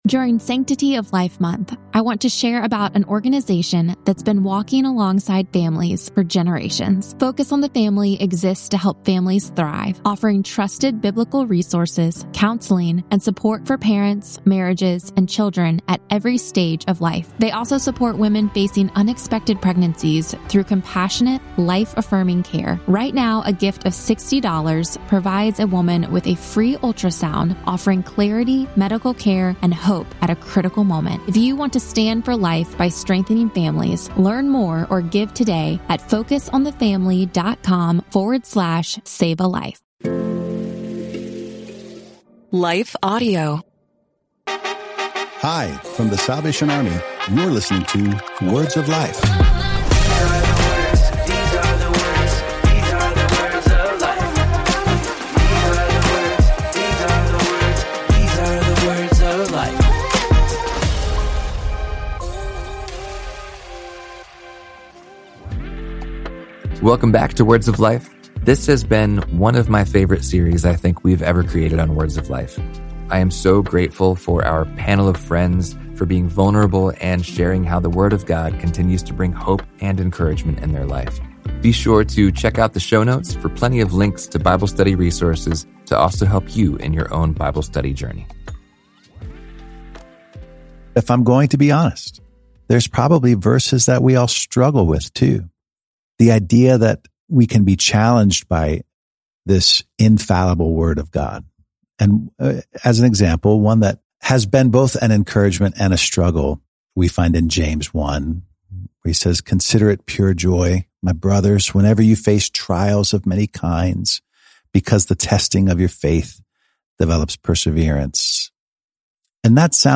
In this heartfelt episode of Words of Life, the panel dives into the reality that even believers wrestle with challenging Scriptures.